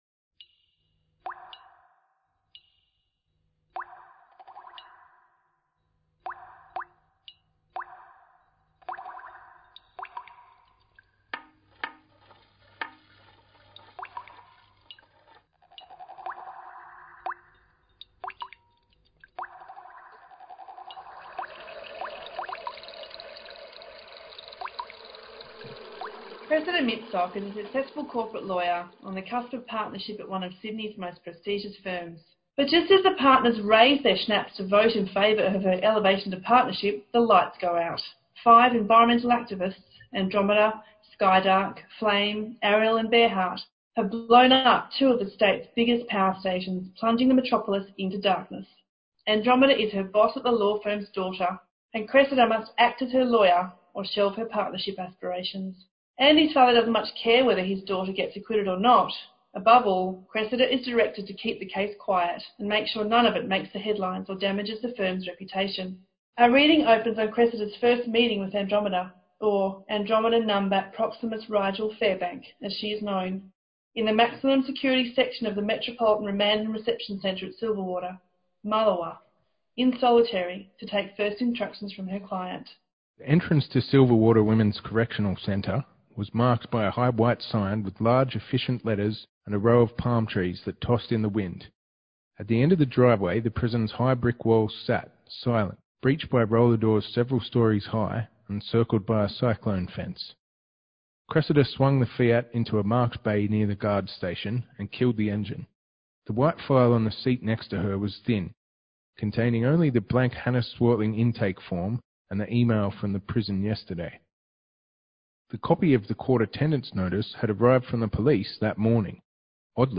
Interviews and Recordings A recording of the ZoomPlay "Direct Action" from the Green Left team featuring presenters from Green Left which is a re-entacted performance of chapter 13 of author J.D. Svenson's climate action thriller 'Direct Action'.